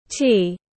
Trà tiếng anh gọi là tea, phiên âm tiếng anh đọc là /tiː/